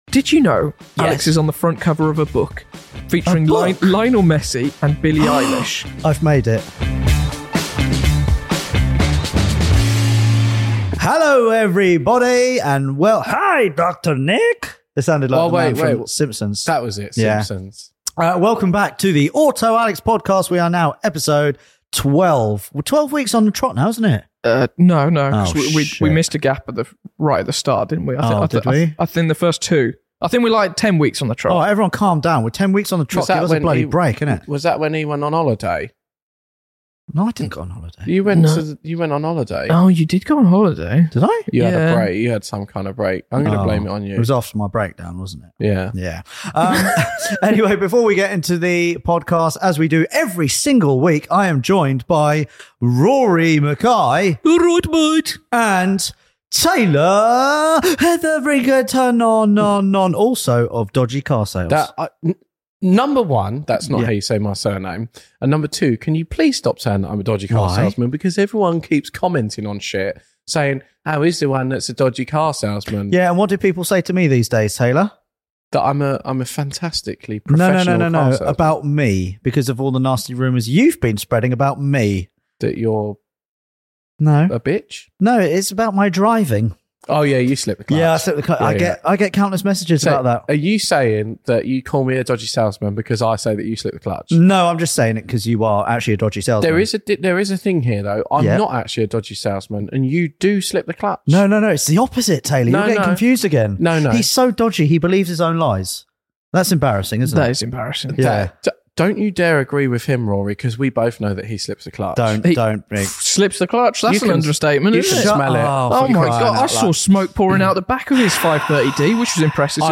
In this week's Podcast, we discuss the best budget winter 4x4s and bad moments that made us want to give up on cars altogether! PLUS, can you identify these cars from their exhaust notes?